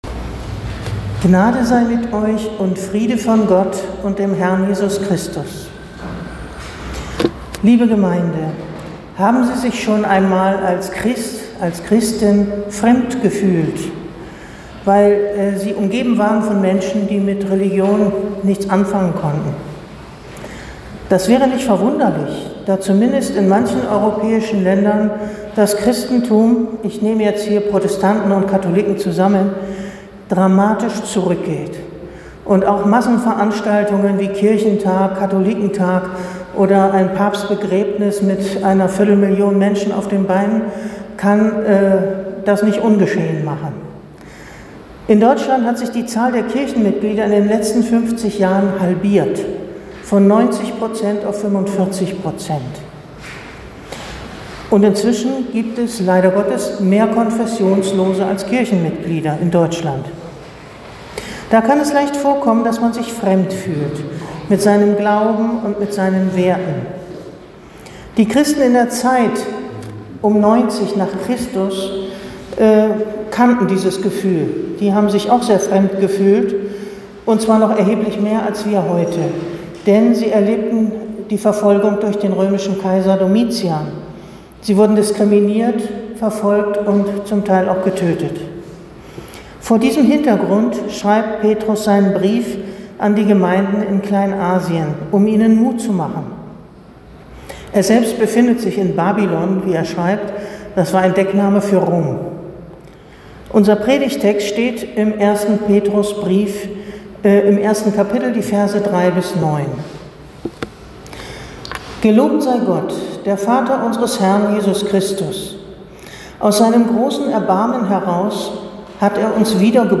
Predigt zu Quasimodogeniti